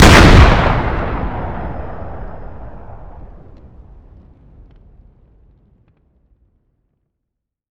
Index of /server/sound/weapons/explosive_m67
m67_explode_5.wav